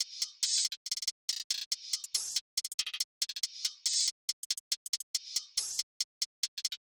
drumloop 9 (140 bpm).wav